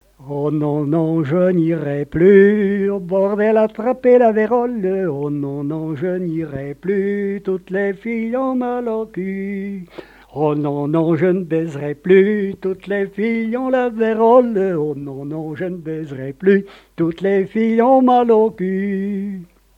danse : quadrille : petit galop
répertoire de chansons et airs à l'accordéon
Pièce musicale inédite